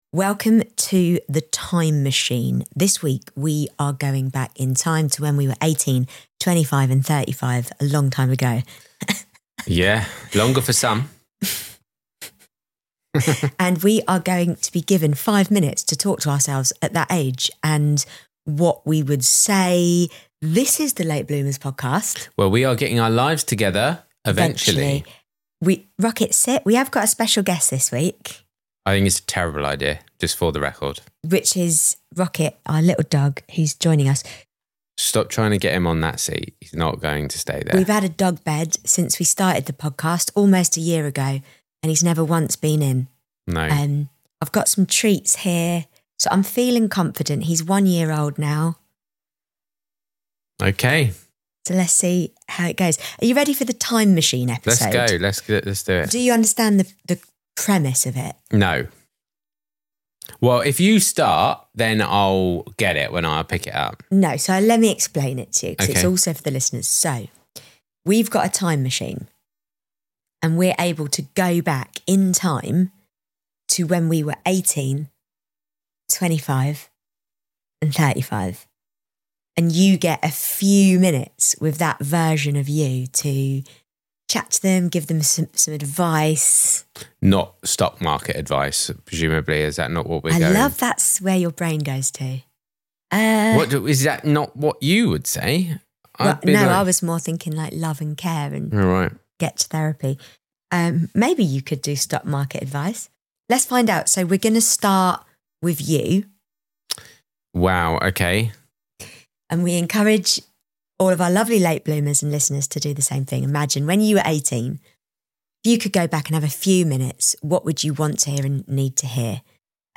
This is a conversation about perspective, forgiveness, and the surprising beauty of hindsight.